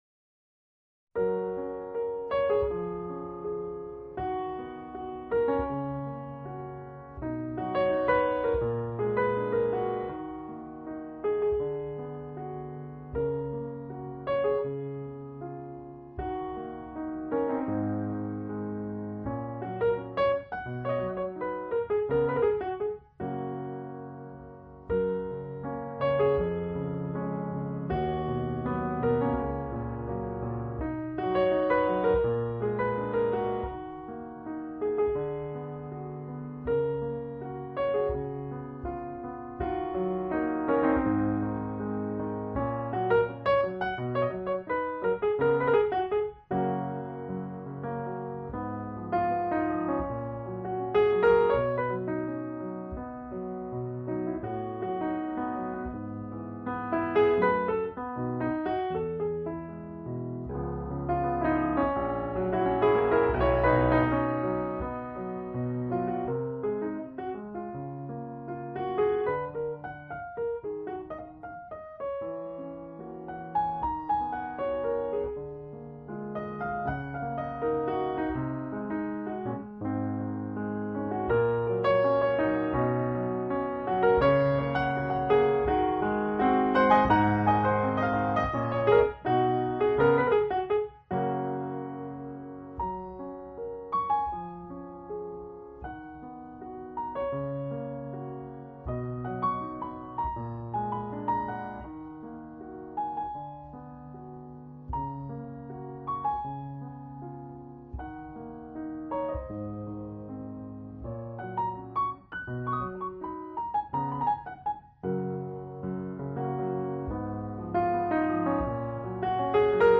钢琴专辑
音乐风格：Jazz